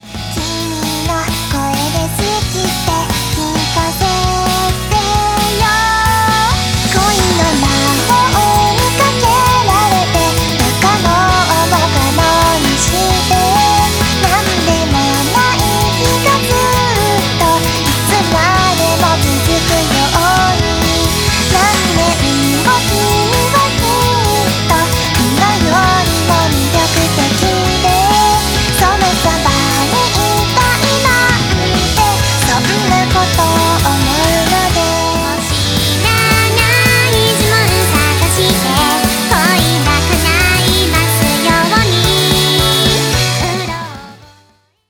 種別/サイズ 音楽CD - ポップス/ 音楽CD 10p